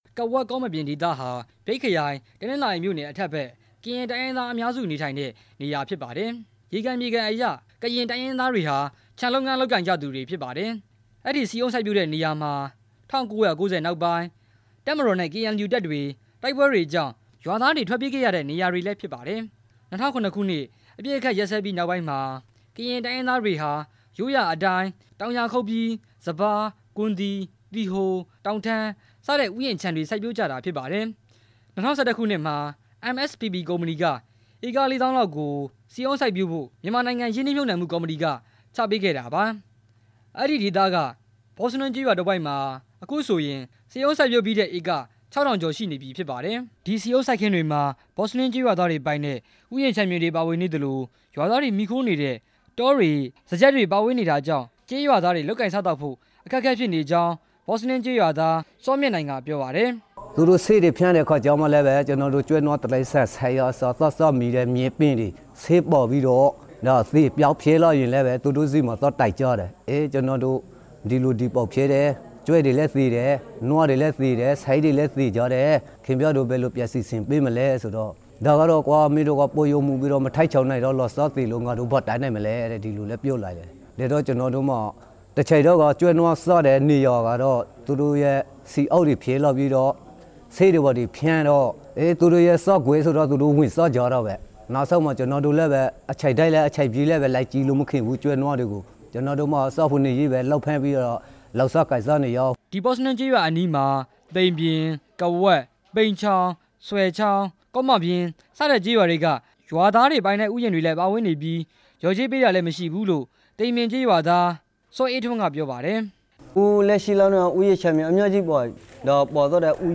မြိတ်ခရိုင် တနင်္သာရီမြို့နယ် အတွင်းက မြေယာပိုင်ဆိုင် မှုပြဿနာအကြောင်း တင်ပြချက်